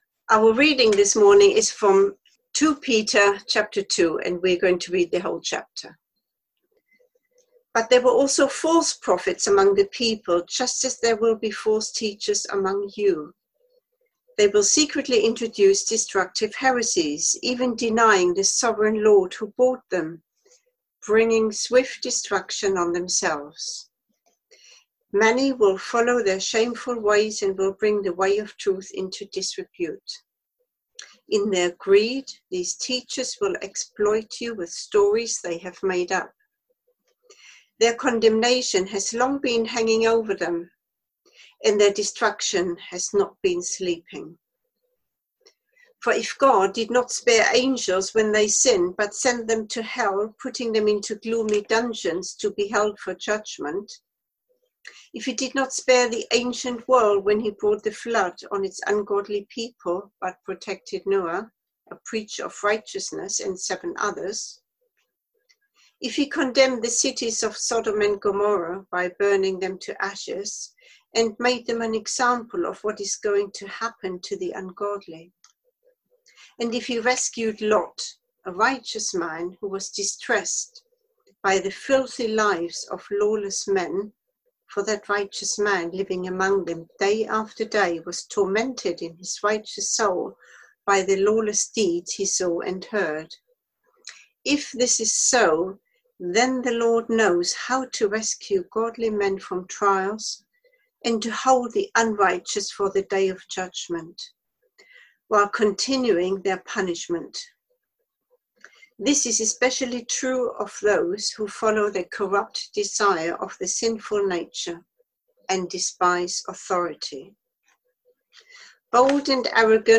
Media for Sunday Service on Sun 26th Jul 2020 10:00
Theme: Sermon